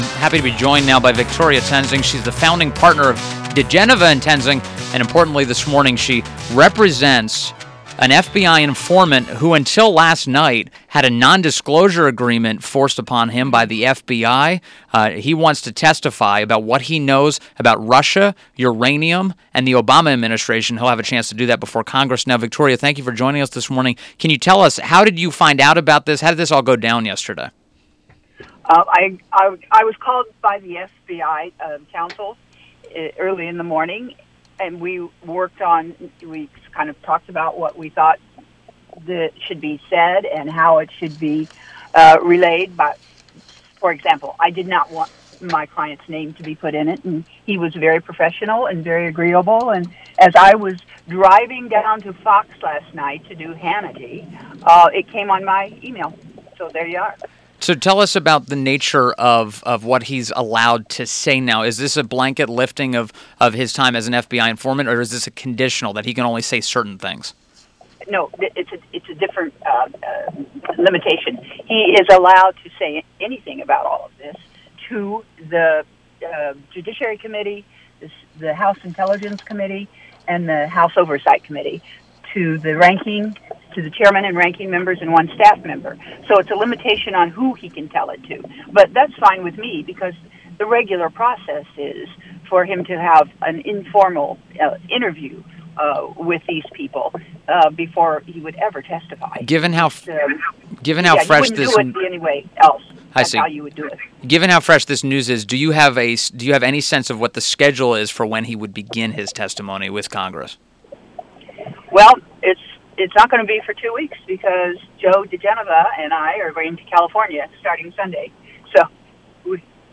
WMAL Interview - VICTORIA TOENSING - 10.26.17